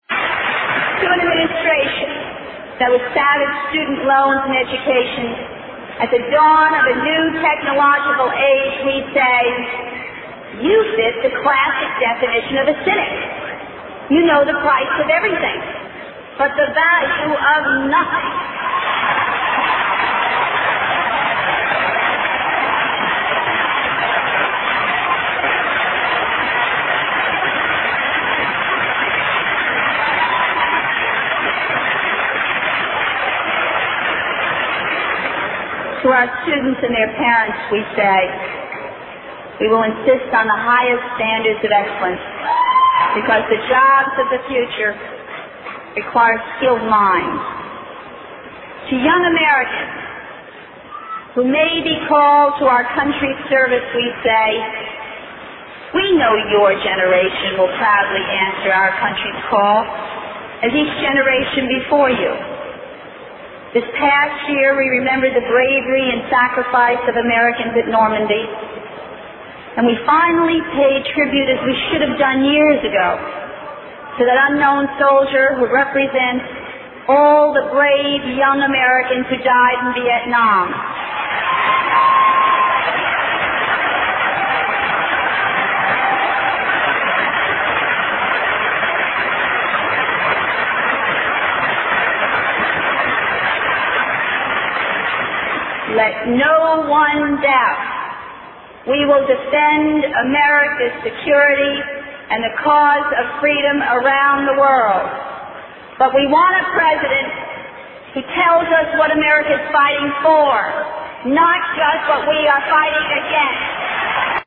经典名人英语演讲(中英对照):Vice-Presidential Nomination Acceptance Speech 7